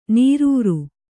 ♪ nīrūru